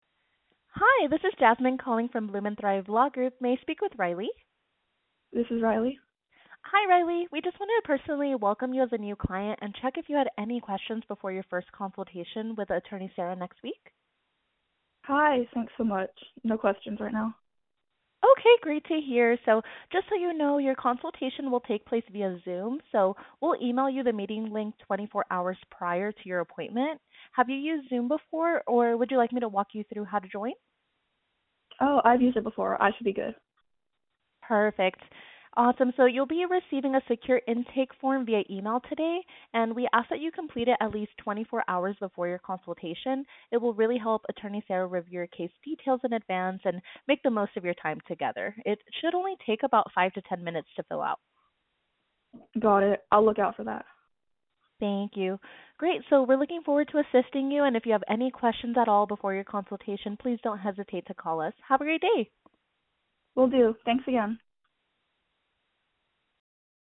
Welcome Call for New Clients
HUMAN RECEPTIONIST
Welcome-Call-New-Clients-Human.mp3